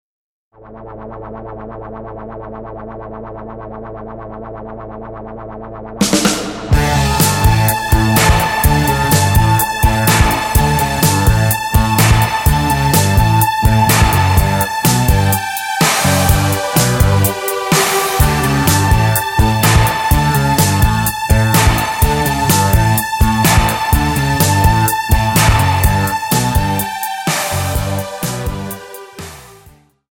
--> MP3 Demo abspielen...
Tonart:A ohne Chor